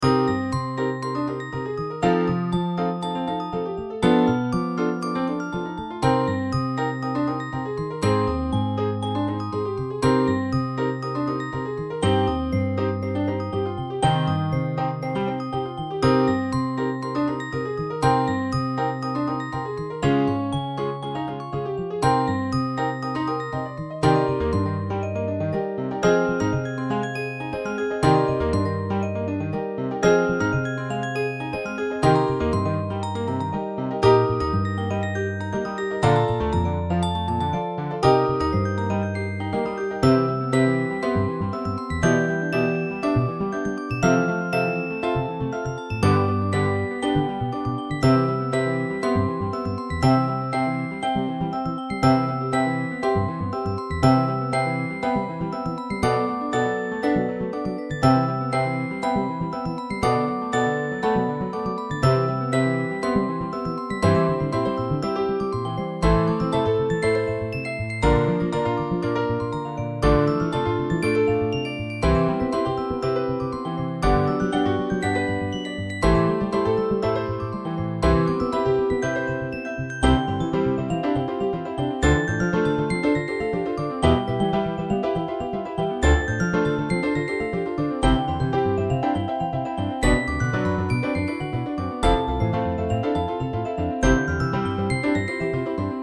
ミュージックボックス、ピアノ、アコースティックベース